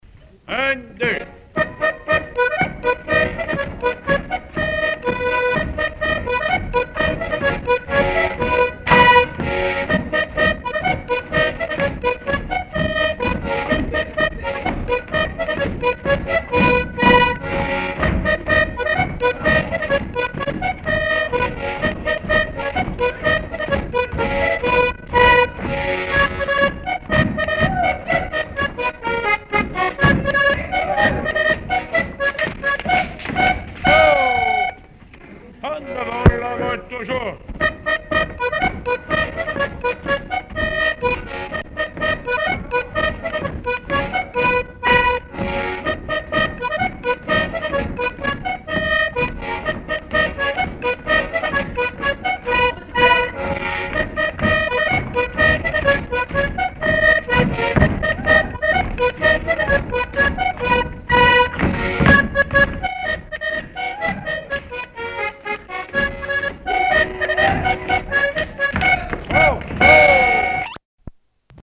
instrumental
danse : quadrille : moulinet
accordéon chromatique
Pièce musicale inédite